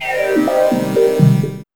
5204L SYNSWP.wav